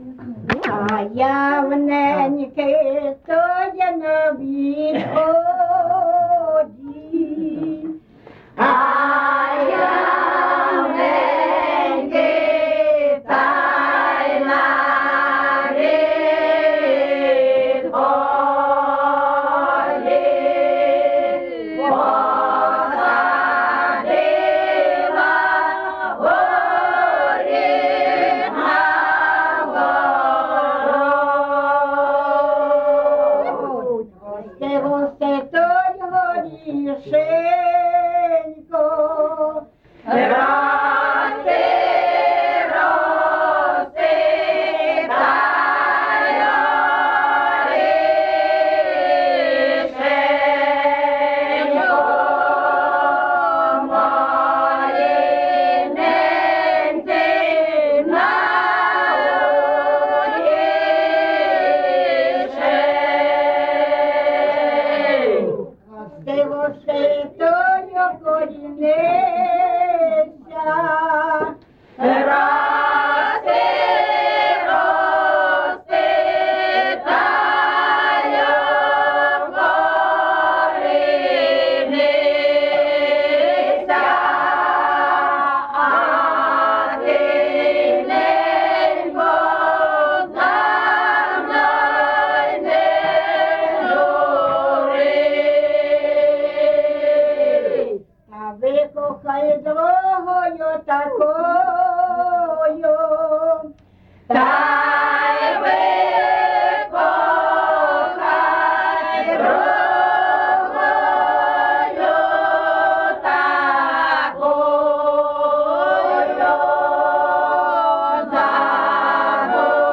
ЖанрВесільні
Місце записус. Шарівка, Валківський район, Харківська обл., Україна, Слобожанщина